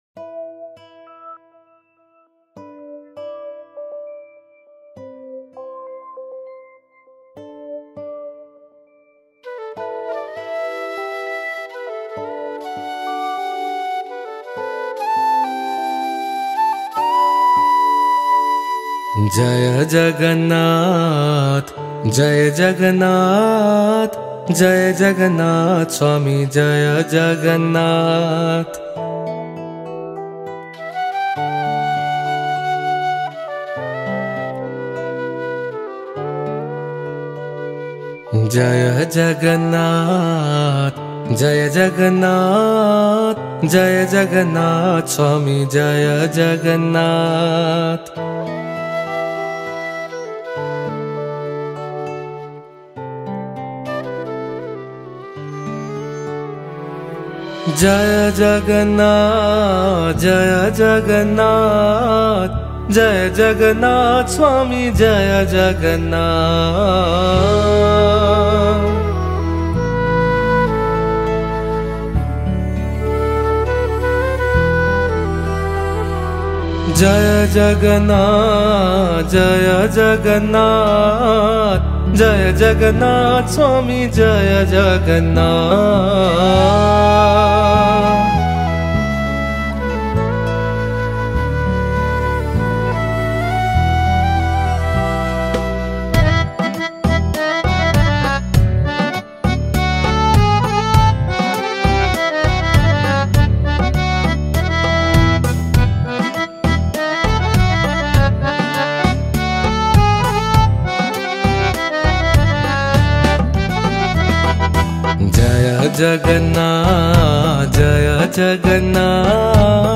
Beautiful Bhajan – Lord Jagannath
THE-BEST-KIRTAN-OF-Lord-Jagannaath.mp3